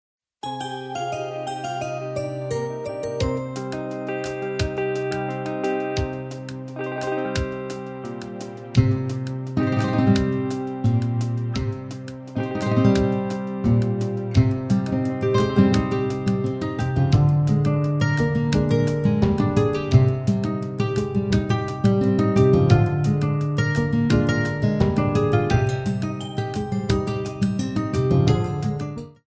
keyboard guitar